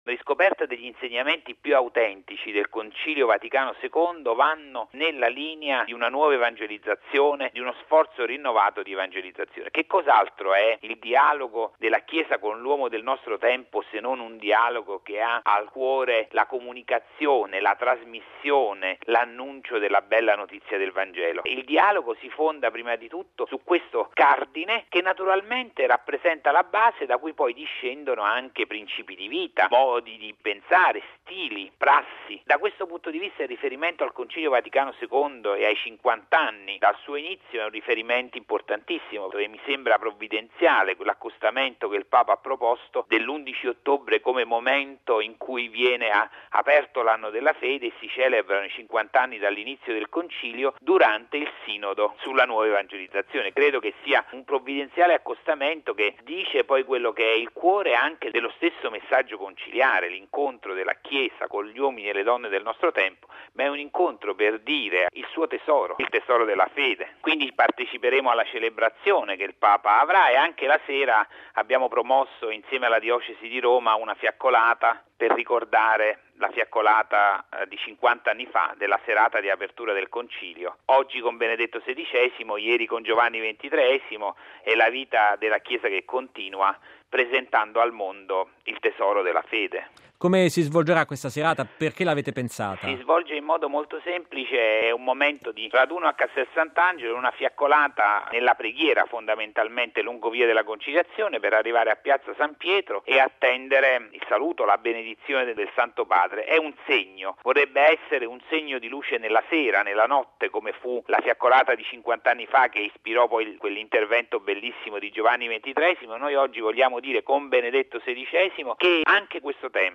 Radiogiornale del 10/10/2012 - Radio Vaticana